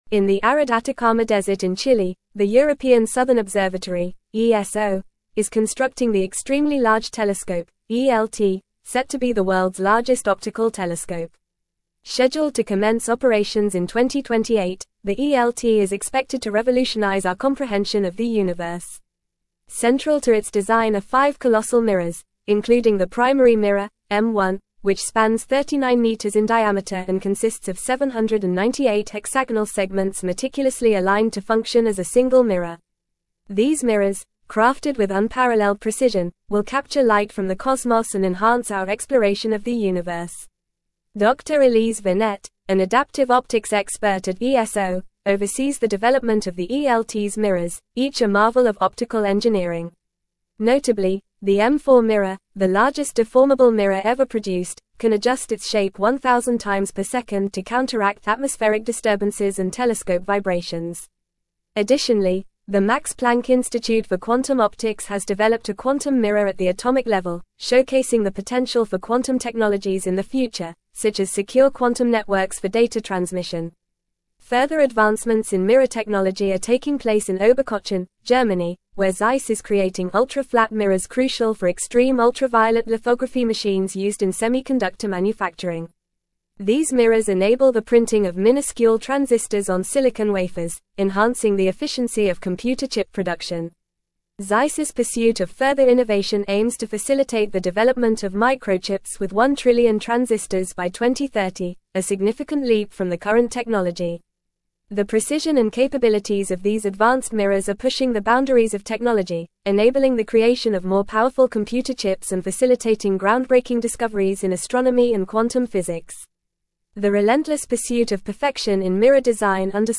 Fast
English-Newsroom-Advanced-FAST-Reading-Advancing-Technology-Through-Precision-The-Power-of-Mirrors.mp3